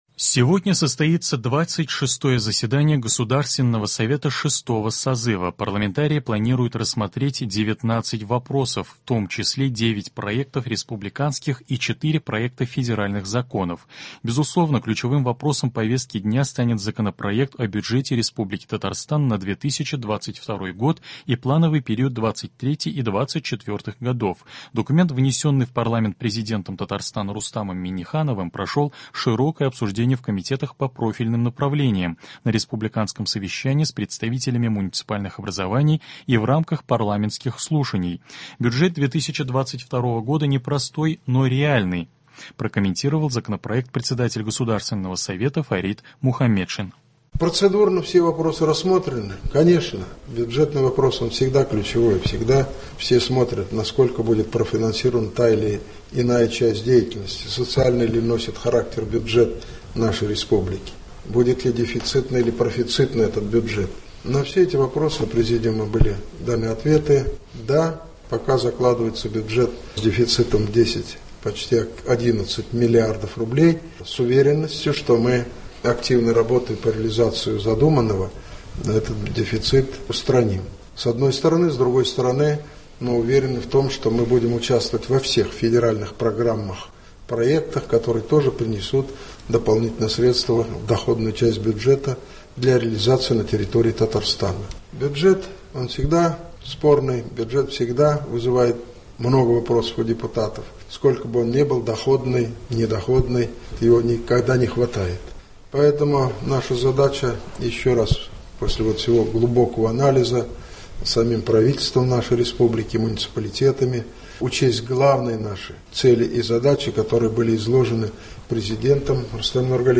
Новости (25.10.21)